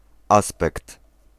Ääntäminen
Synonyymit apariencia tez rostro pinta fisonomía Ääntäminen Haettu sana löytyi näillä lähdekielillä: espanja Käännös Ääninäyte 1. aspekt {m} 2. mina {f} 3. wygląd {m} 4. wyraz twarzy {m} Suku: m .